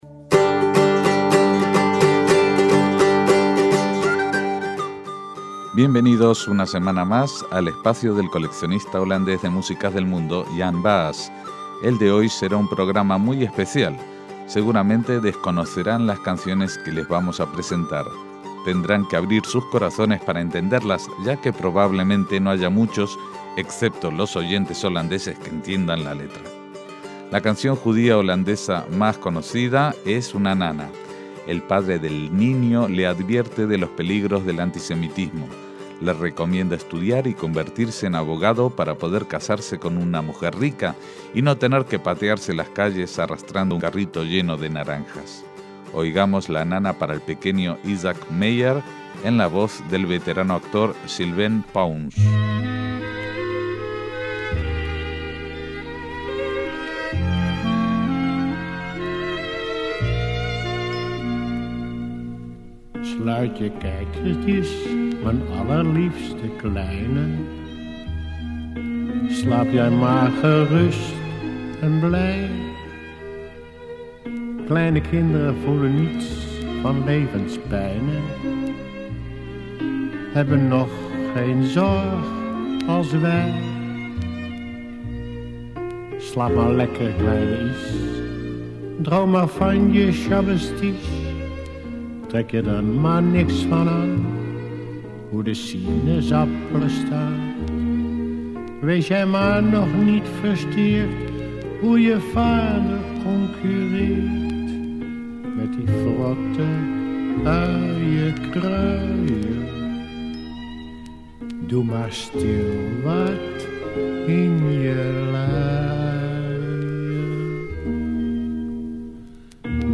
Canciones judías holandesas